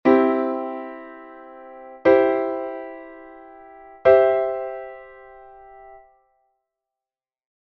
Ao igual que os intervalos, os acordes poden investirse, colocando as distintas notas que o forman na parte máis grave do mesmo.
DO-MI-SOL; MI-SOL-DO; SOL-DO-MI
inversiones_triada.mp3